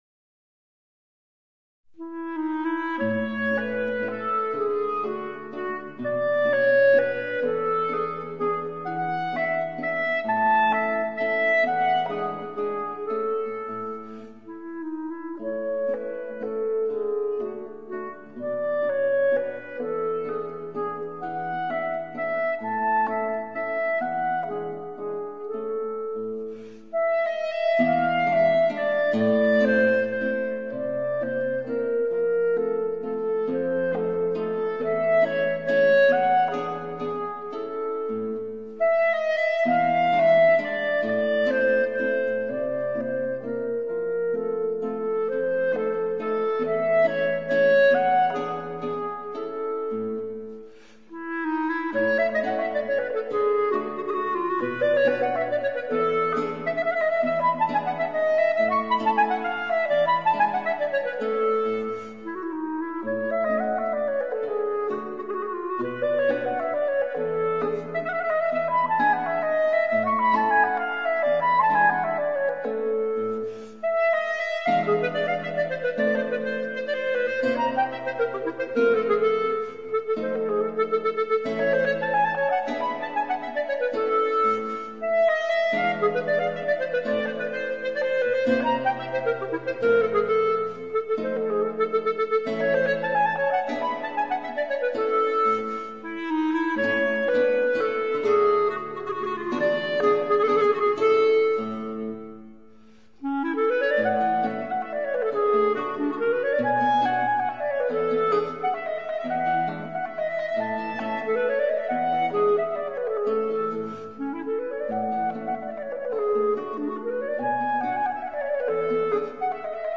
La Serenata op. 21 per clarinetto in La (parte in Sib inclusa) e chitarra fa parte di un gruppo di sei Serenate per clarinetto e chitarra di Heinrich Neumann (1792-1861) che rappresentano un “unicum” nella storia della letteratura per questo insolito organico strumentale.